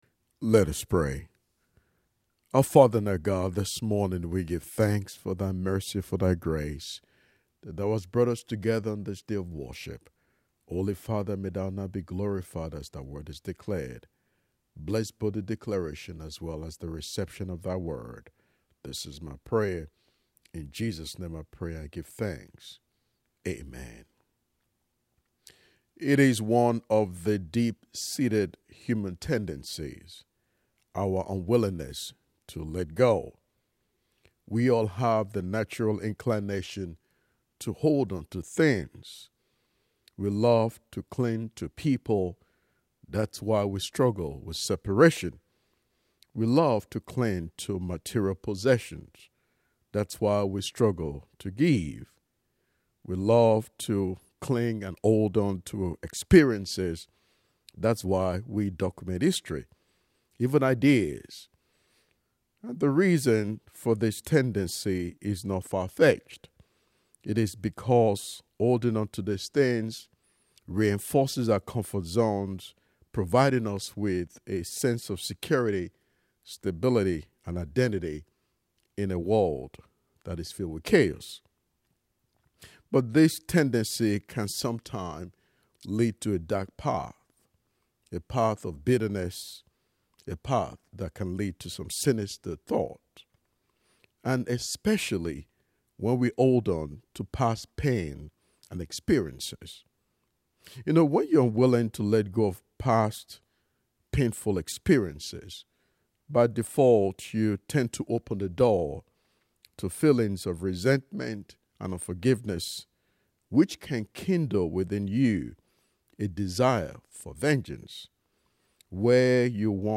10:30 AM Service